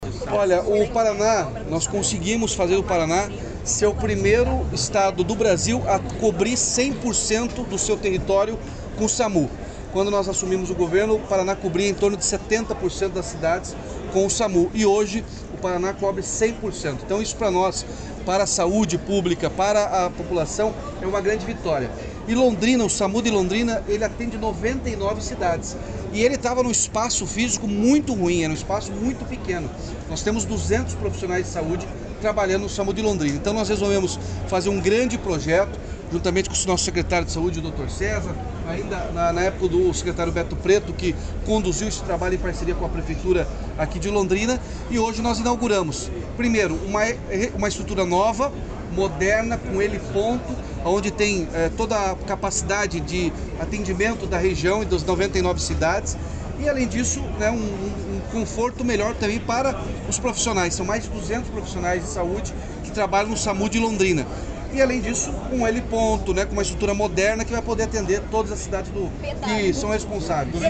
Sonora do governador Ratinho Junior sobre o novo SAMU de Londrina
SONORA RATINHO JUNIOR SAMU LONDRINA TRECHO.mp3